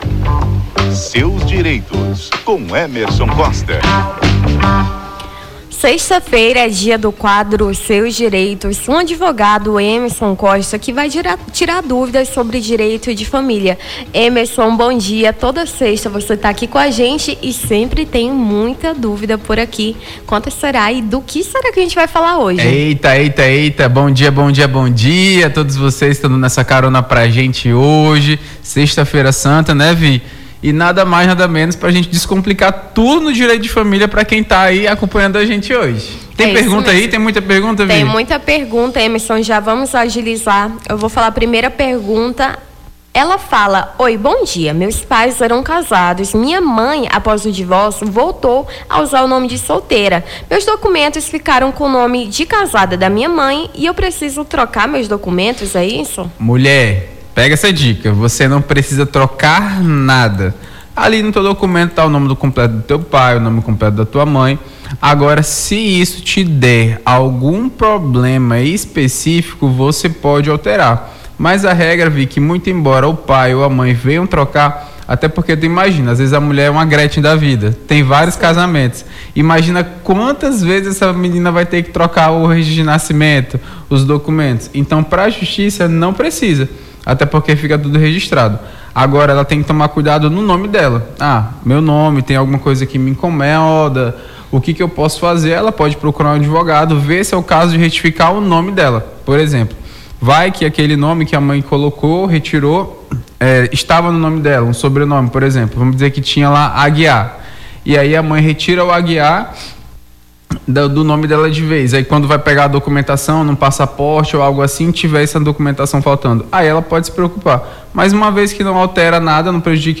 Seus Direitos: advogado esclarece dúvidas dos ouvintes sobre Direito de Família